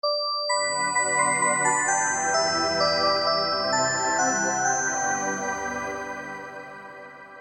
Darmowe dzwonki - kategoria Świąteczne
Klasyczny dzwonek na święta.